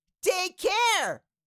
Cartoon Little Child, Voice, Take Care Sound Effect Download | Gfx Sounds
Cartoon-little-child-voice-take-care.mp3